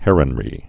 (hĕrən-rē)